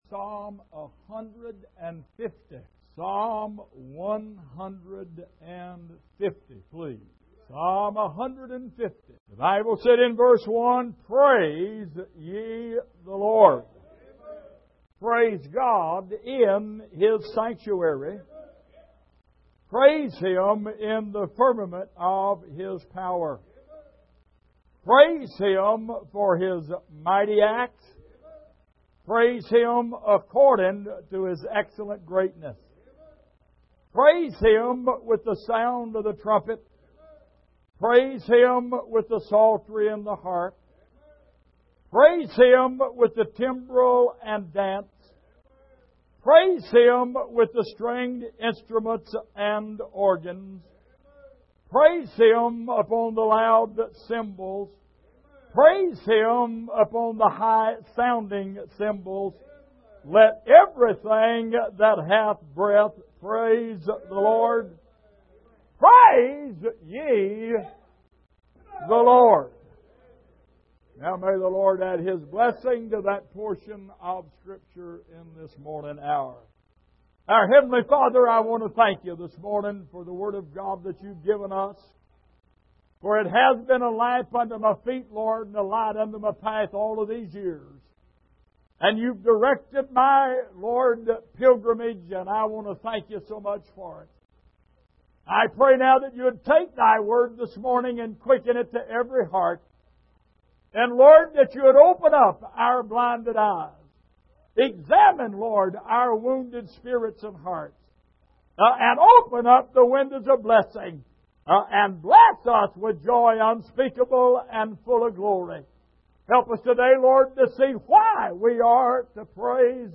Passage: Psalm 150:1-6 Service: Sunday Morning